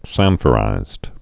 (sănfə-rīzd)